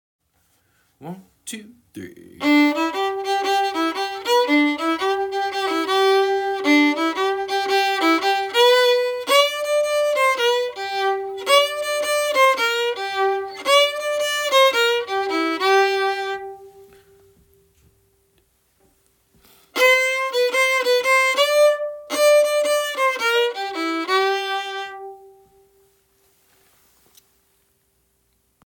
Milk Cow Blues melody (MP3)Download